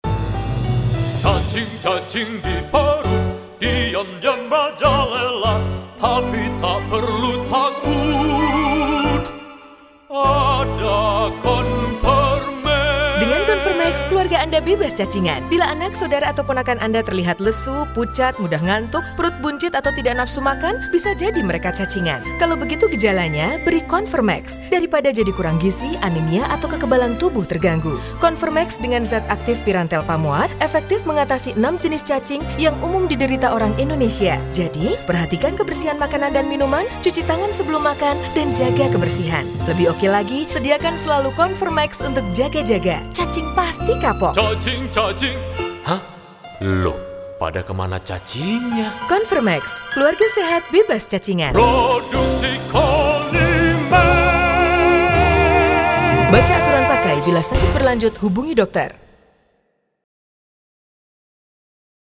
Iklan Radio Konvermex
Direkam : 106.4 Sindo Prima FM